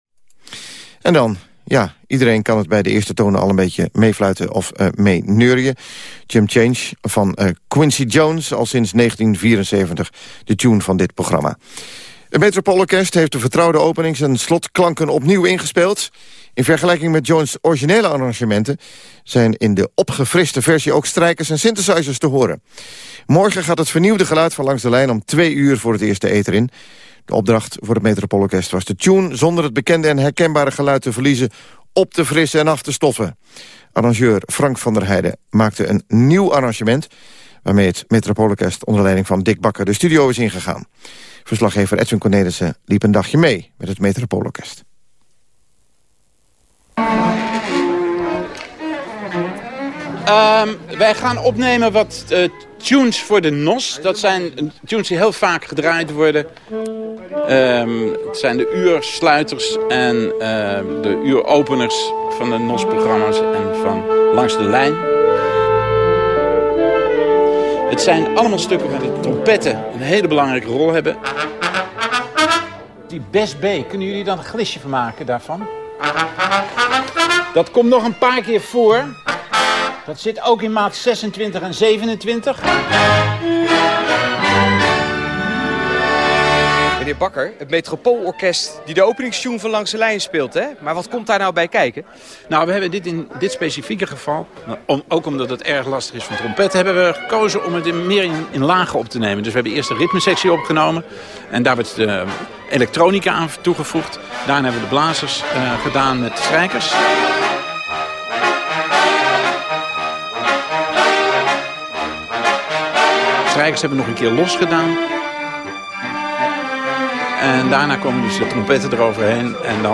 Strijkers en synthesizers zorgen voor een nieuw klankbeeld, zonder het originele stuk tekort te doen.”
reportage.mp3